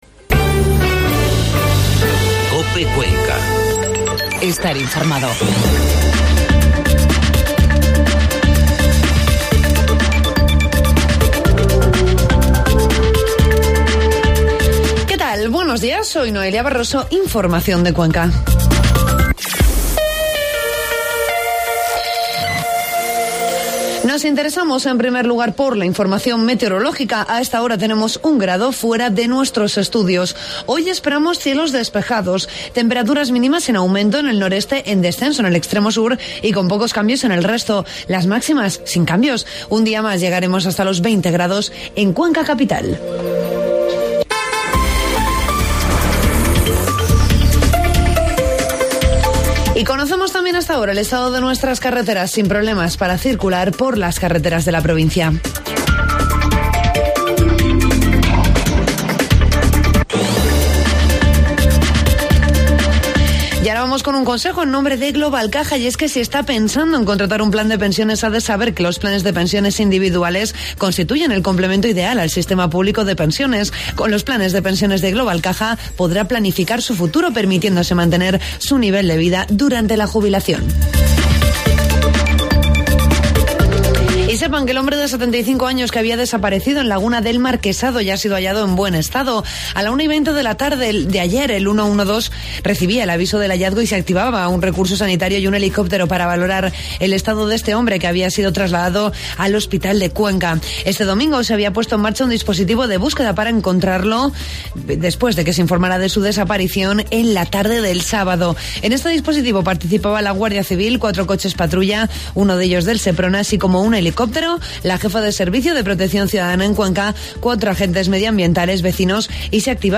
Informativo matinal COPE Cuenca 20 de noviembre